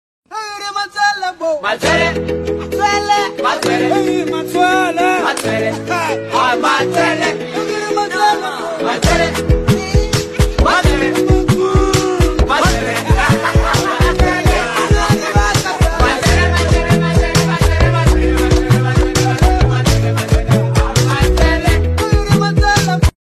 old tigrigna music